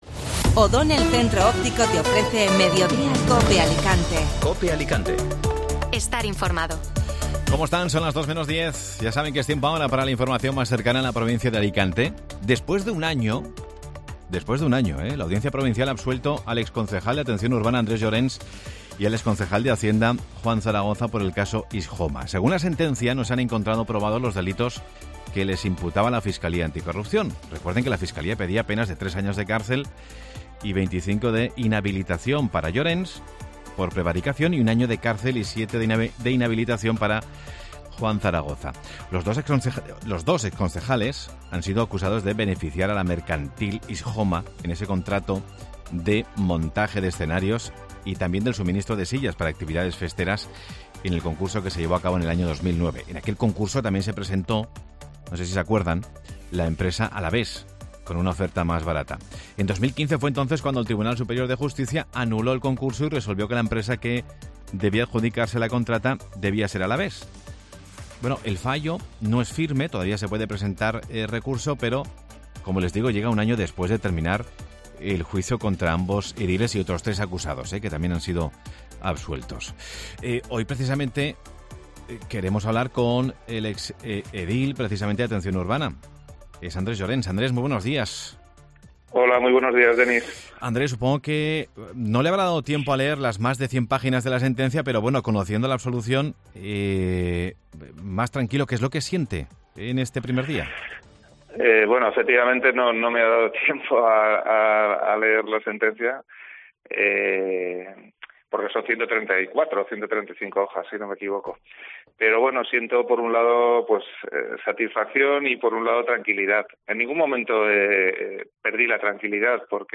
Escucha la entrevista al exconcejal de Alicante tras conocer la sentencia absolutoria en el caso Isjoma
Entrevista a Andrés Llorens, exconcejal de Atención Urbana